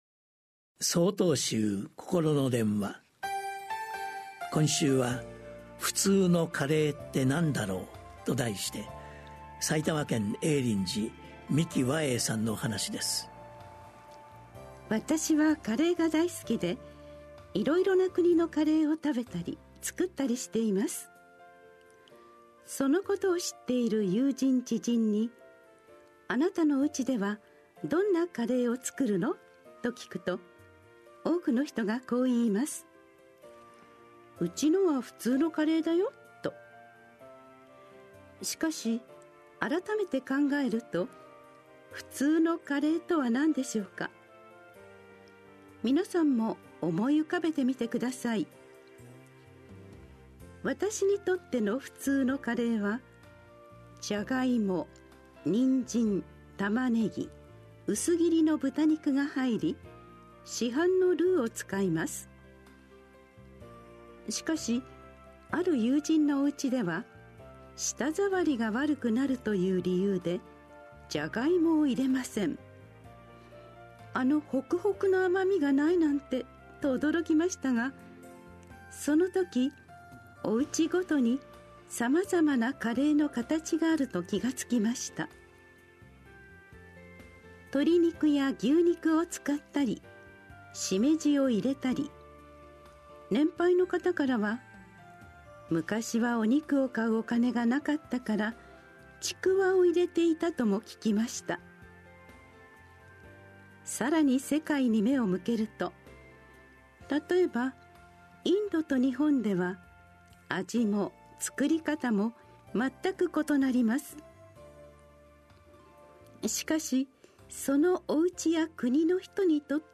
心の電話（テレホン法話）５/13公開『普通のカレーって何だろう』 | 曹洞宗 曹洞禅ネット SOTOZEN-NET 公式ページ